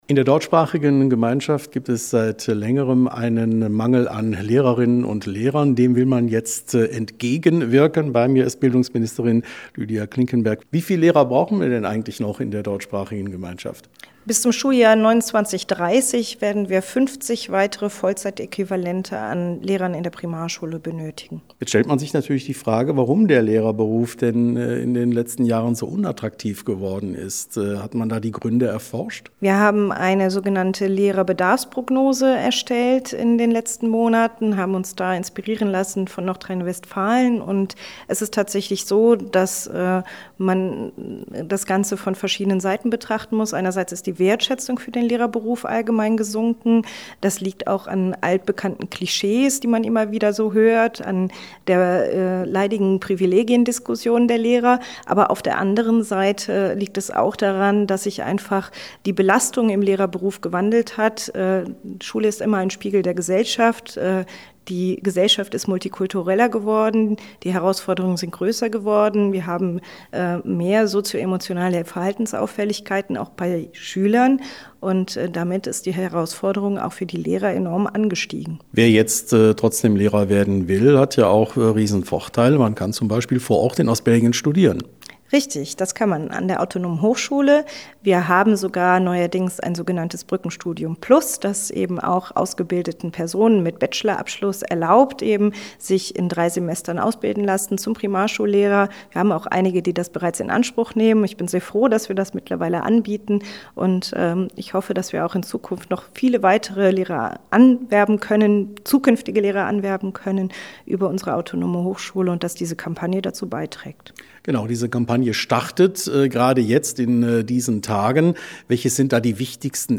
sprach mit Bildungsministerin Lydia Klinkenberg: